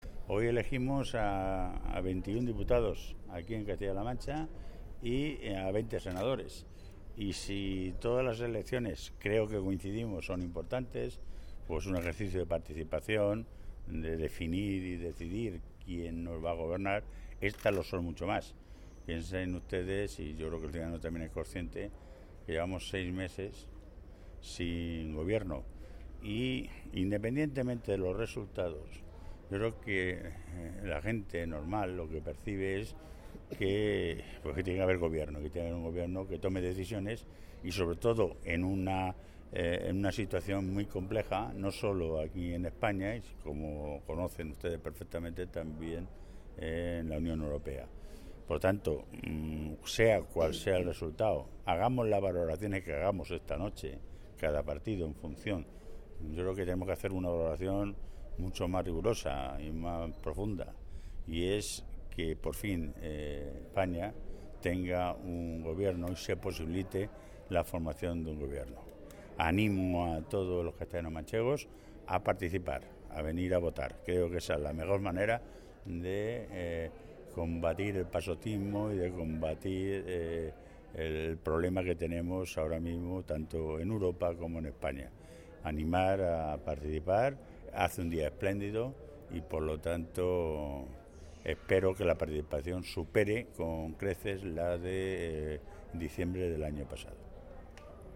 Fernández Vaquero que ha realizado estas manifestaciones tras ejercer su derecho al voto en el colegio “Alfonso VI” de Toledo, ha destacado la importancia de estos comicios tras seis meses sin gobierno en nuestro país.
Cortes de audio de la rueda de prensa